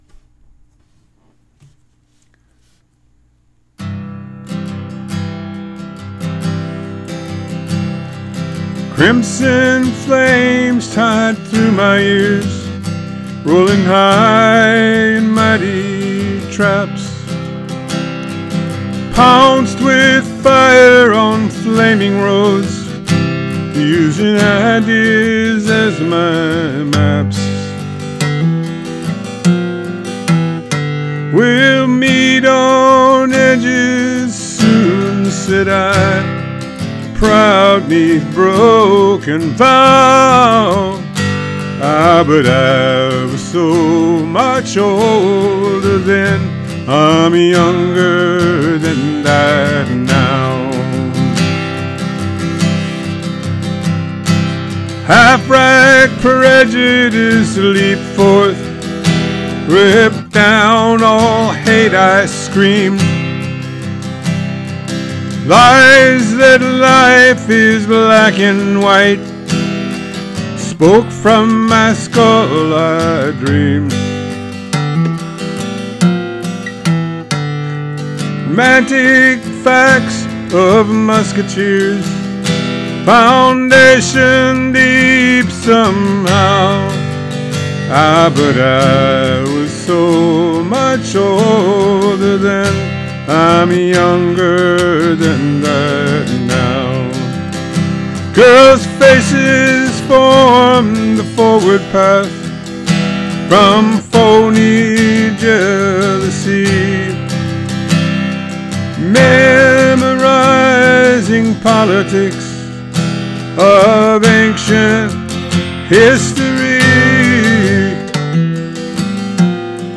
Here’s my cover of this powerful song.